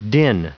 Prononciation du mot din en anglais (fichier audio)
Prononciation du mot : din